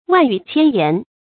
萬語千言 注音： ㄨㄢˋ ㄧㄩˇ ㄑㄧㄢ ㄧㄢˊ 讀音讀法： 意思解釋： 許許多多的話語。